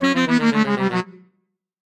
GameFail.mp3